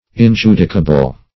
Injudicable \In*ju"di*ca*ble\